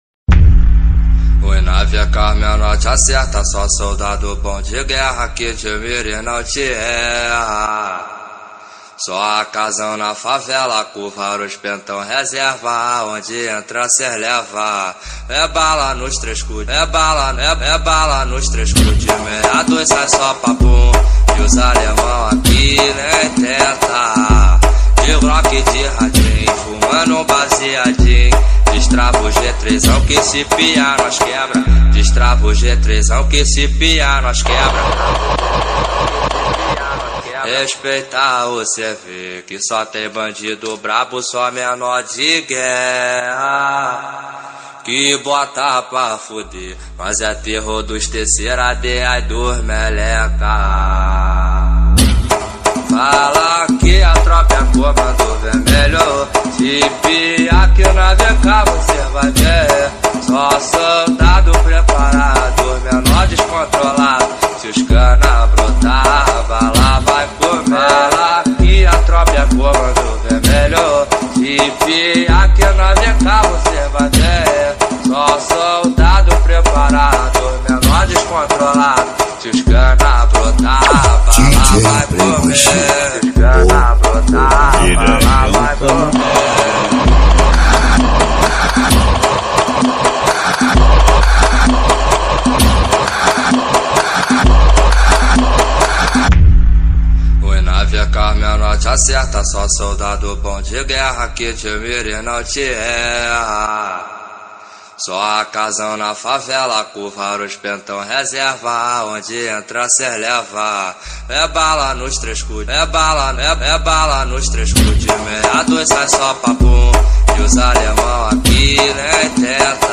2024-09-17 08:25:42 Gênero: Rap Views